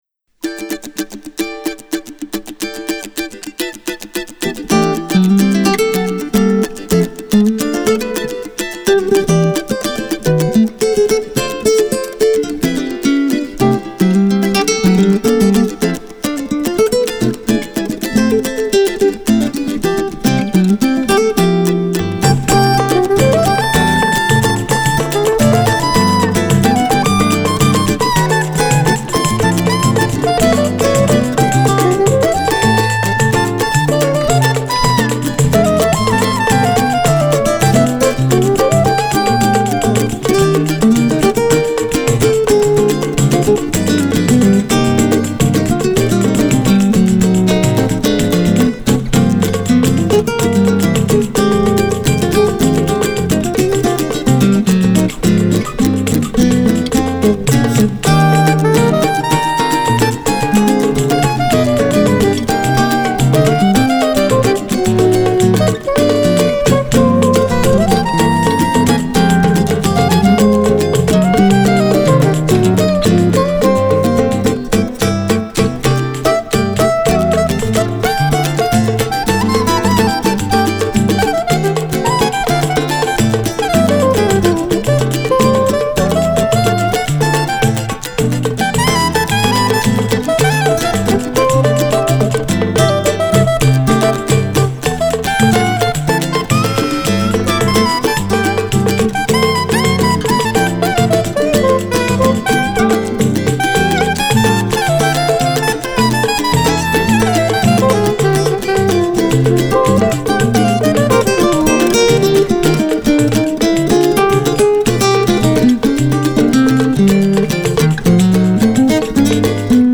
Brésil / Choro / Capoeira
Guitare, Cavaquinho, Percussions
Saxophone Soprano